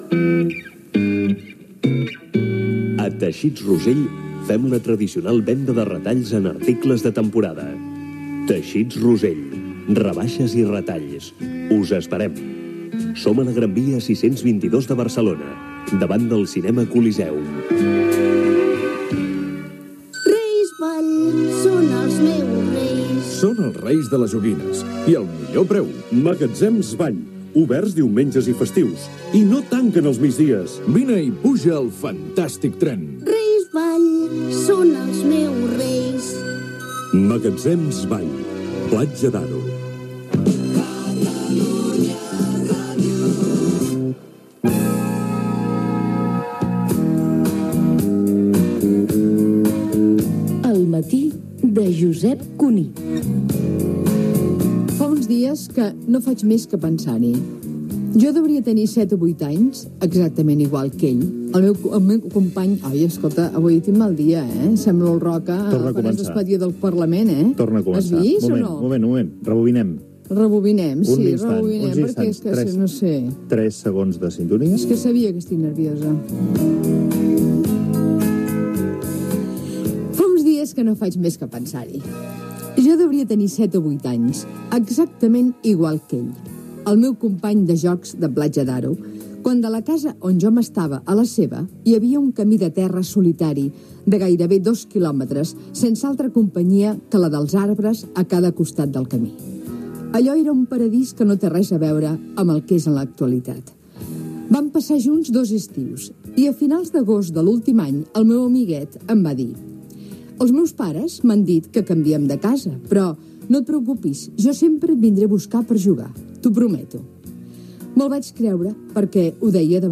Publicitat,indicatiu de la ràdio
Info-entreteniment